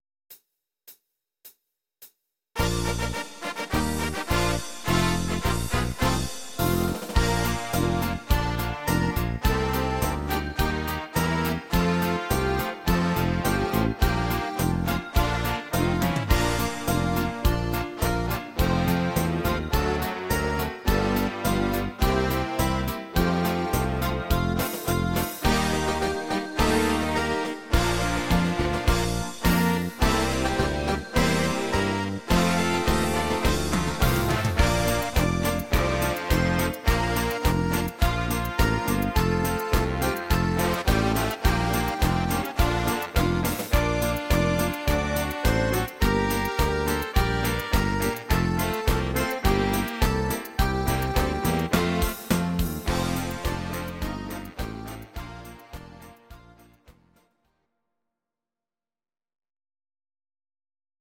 These are MP3 versions of our MIDI file catalogue.
Please note: no vocals and no karaoke included.
Kölsch Karnevalslied